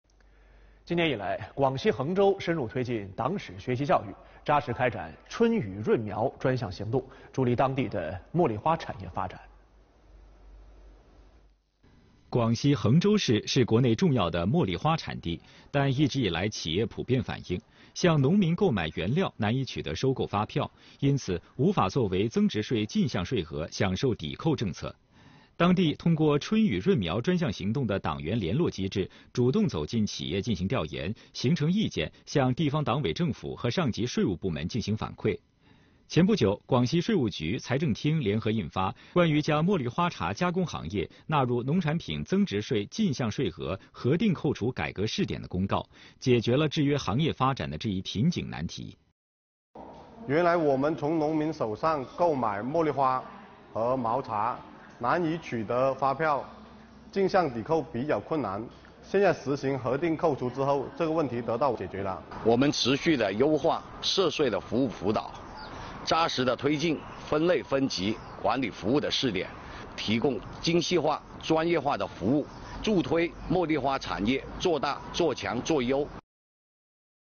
来源：中央电视台新闻频道